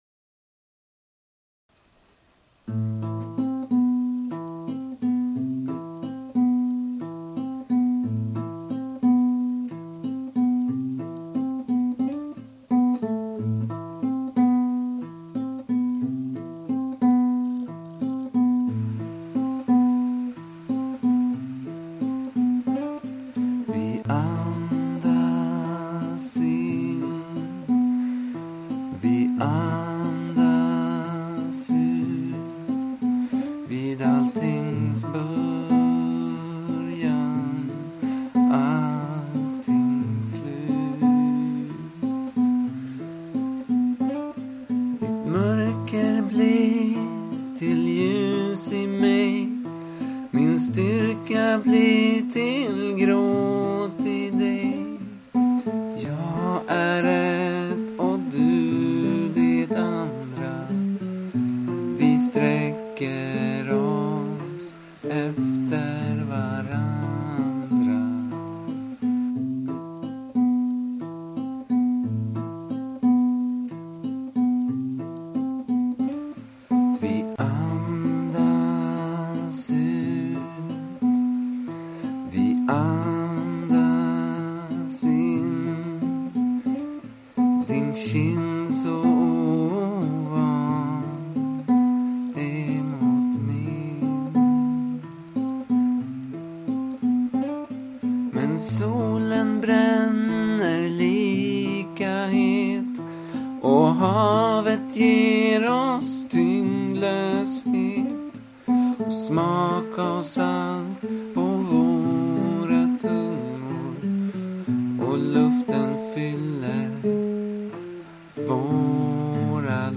inspelningen är gjord i instuderingssyfte